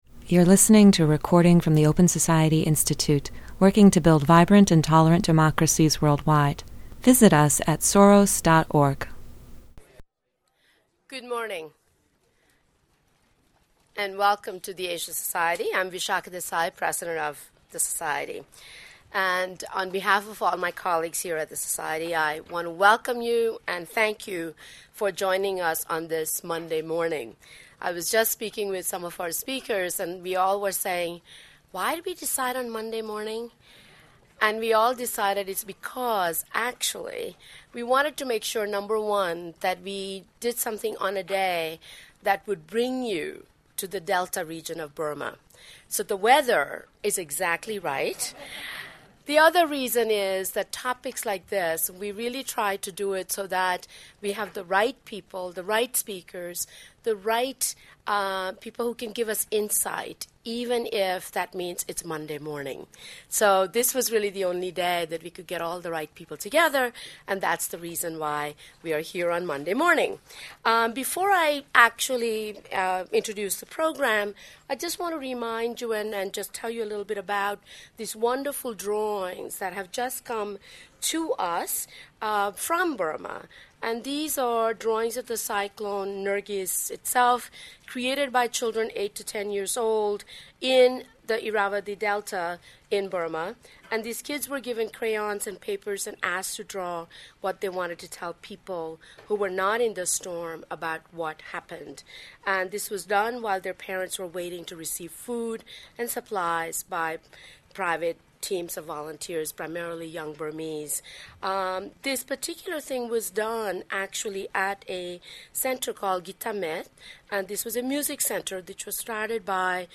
A panel assesses the progress of aid and the reception by the country’s ruling junta.